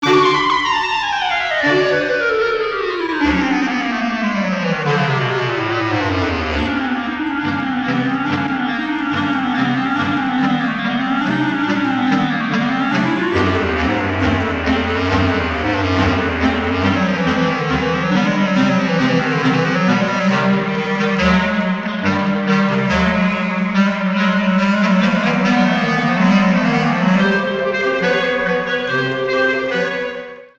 für Klarinettenquintett